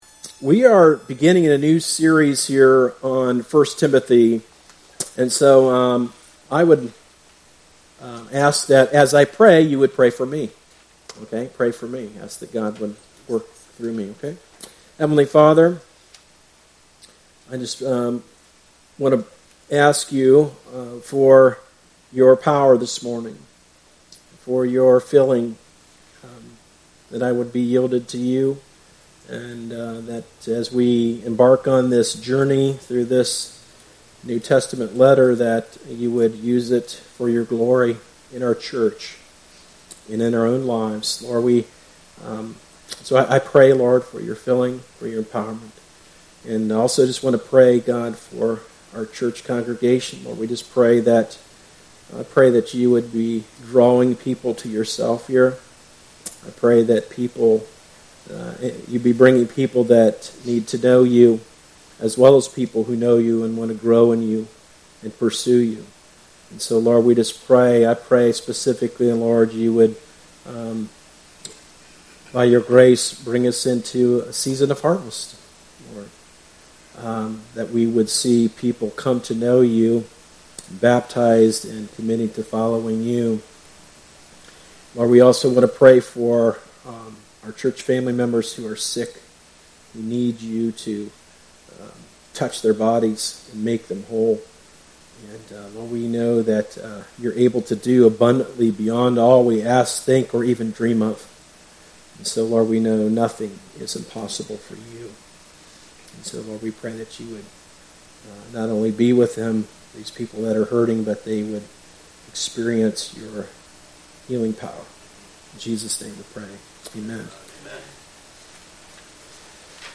Weekly sermon podcast from Darby Creek Church in Galloway, OH.